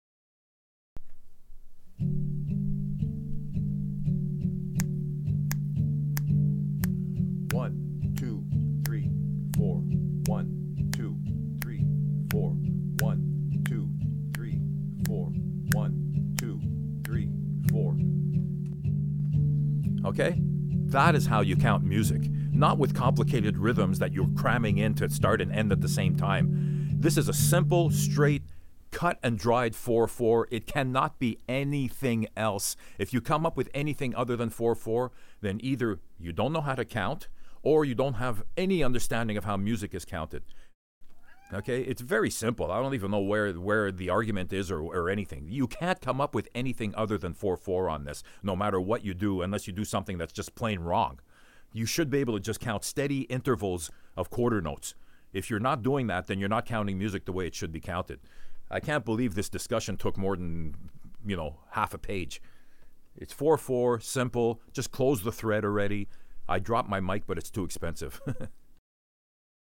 This is how you count music. This is 4/4 and nothing else.: Attachments 4 frickin 4.mp3 4 frickin 4.mp3 1.1 MB · Views: 137